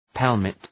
Προφορά
{‘pelmıt}